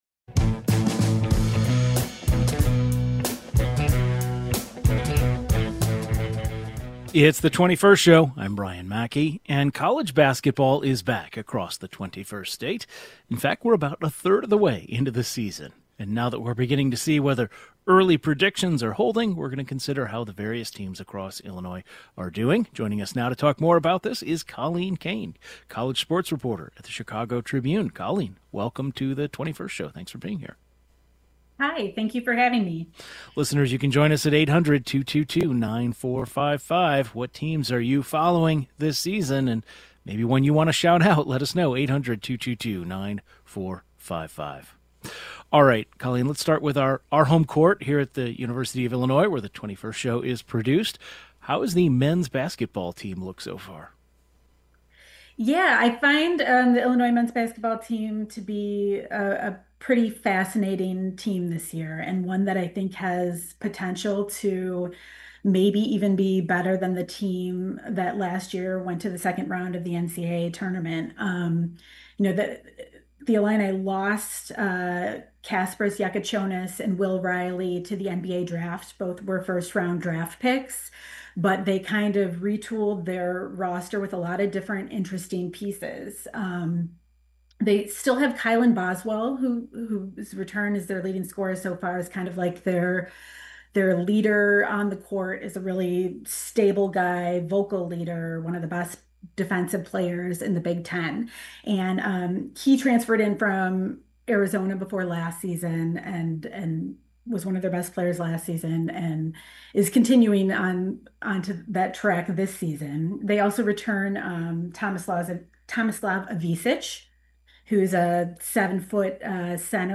The 21st Show is Illinois' statewide weekday public radio talk show, connecting Illinois and bringing you the news, culture, and stories that matter to the 21st state.
And now that we’re beginning to see whether early season predictions are holding, we’re going to consider how the various teams across Illinois are doing and assess what teams could have a chance to join the big dance in March. A sports reporter gives her take and predications.